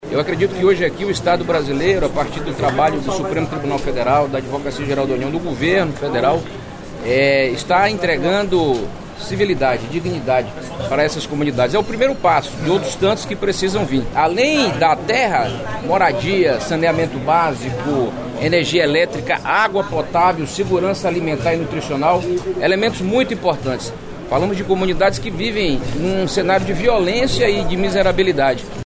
O advogado-geral da União, Jorge Messias, falou sobre a necessidade do acordo.